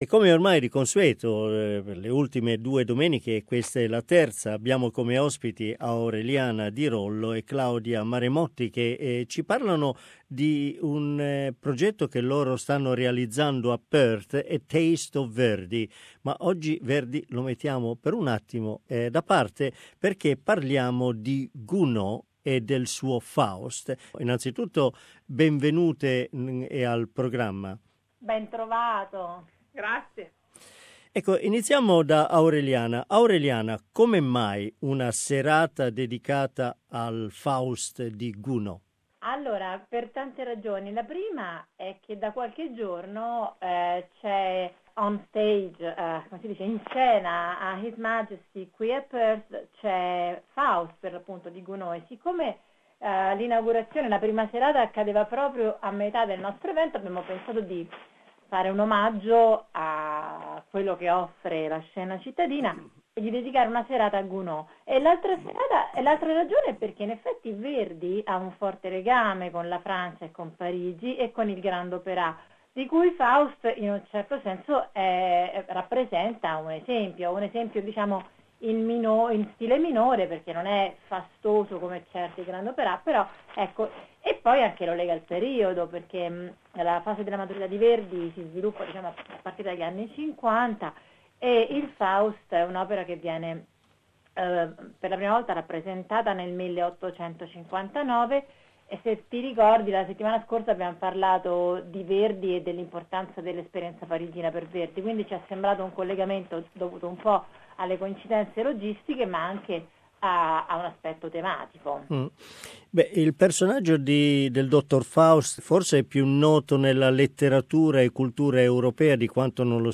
A new conversation with food and music lovers